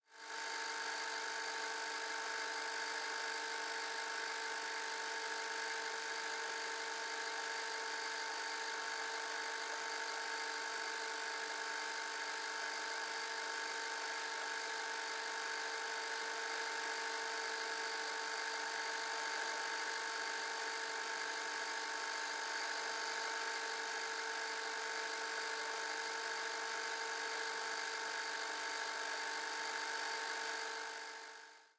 На этой странице вы найдете подборку звуков видеомагнитофона: характерное жужжание двигателя, щелчки кнопок, фоновые шумы аналоговой записи.
Фоновый звук работающего видеопроигрывателя VHS